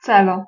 Ääntäminen
Synonyymit mean reason plan subject matter topic object intention target intend determination intended resolution set out aim goal function end Ääntäminen US : IPA : [ˈpɝ.pəs] Tuntematon aksentti: IPA : /ˈpɜː(ɹ).pəs/